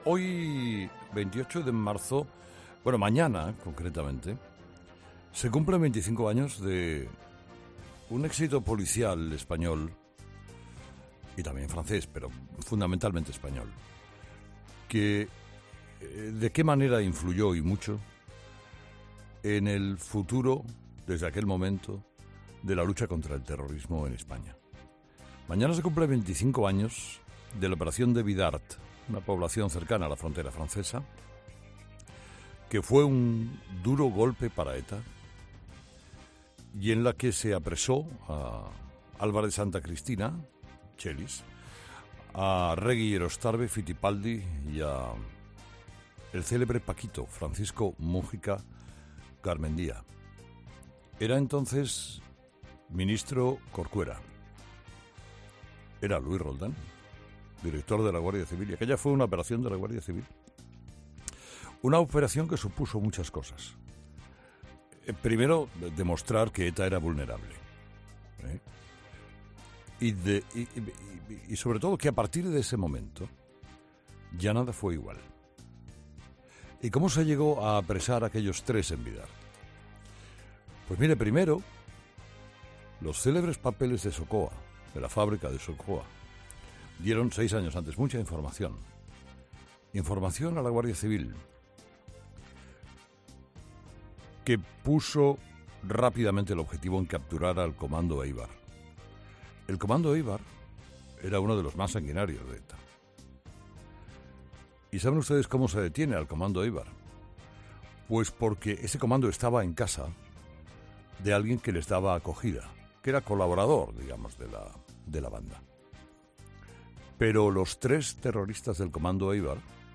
Monólogo de las 8 de Herrera
Este miércoles se cumplen 25 años de la operación de la Guardia Civil que acabó con la cúpula de la banda terrorista ETA en Bidart, al sur de Francia. Lo ha contado Carlos Herrera en su monólogo de las 8 de la mañana.